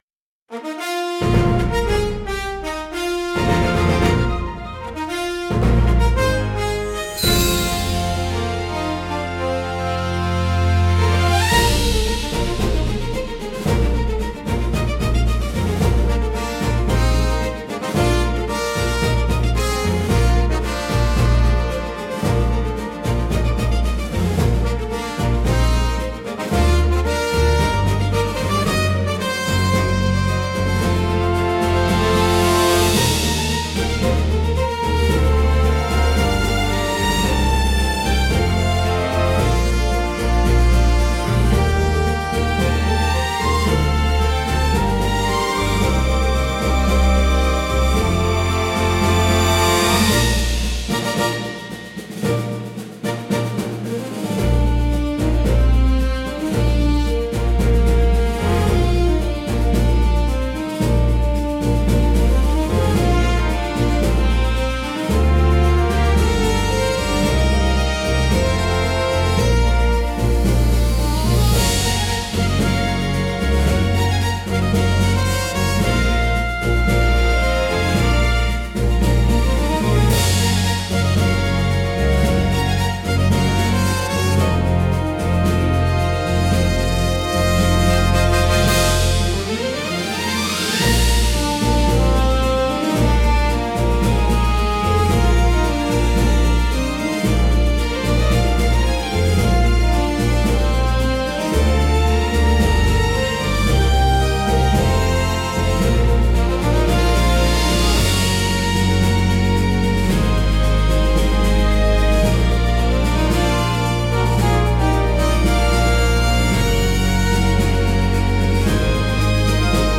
高揚感と喜びを強調し、場の雰囲気を盛り上げる役割を果たします。華やかで勢いのあるジャンルです。